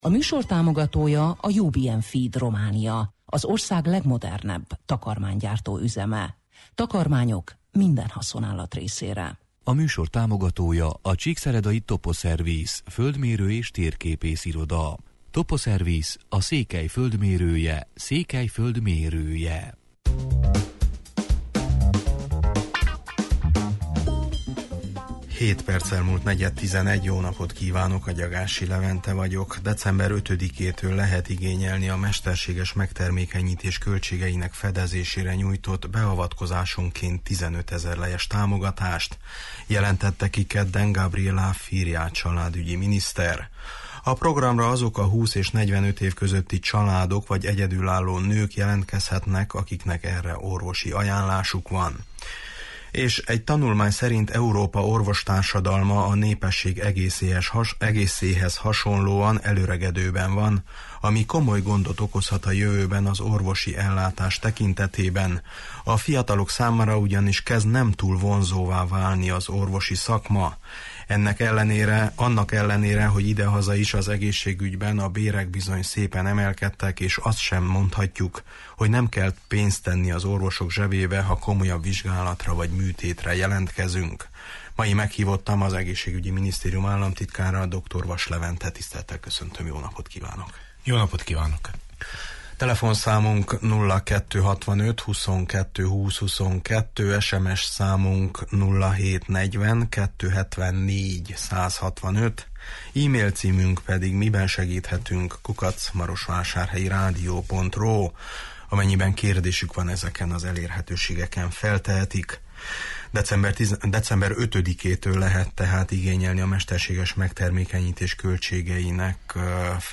Az Egészségügyi Minisztérium államtitkára, dr. Vass Levente volt a vendégünk.